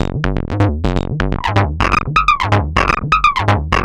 tx_perc_125_outacontrol3.wav